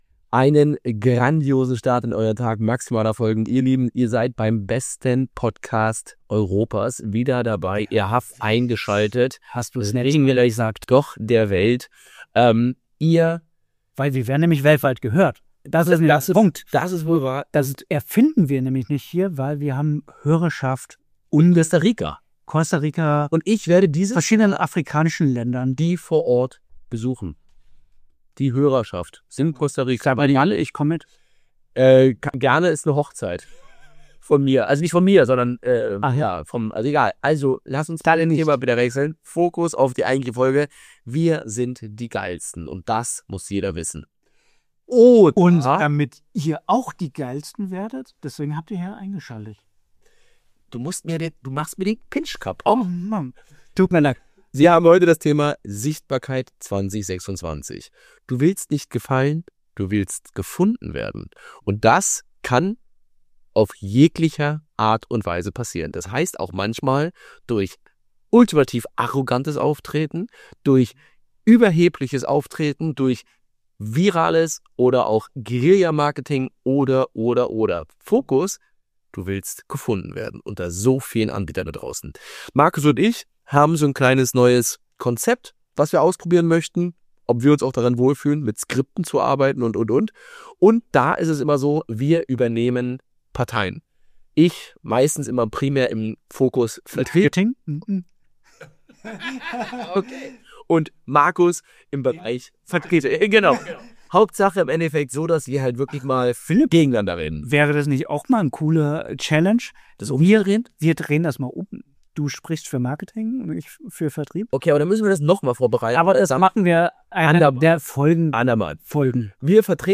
Welche Voraussetzungen du schaffen musst, um sichtbar zu sein Warum es nicht mehr reicht, nur auf einen Kanal zu setzen Wie immer wird diskutiert, argumentiert und auch mal provoziert – mit dem Ziel, dir neue Perspektiven zu geben und dich zum Nachdenken zu bringen.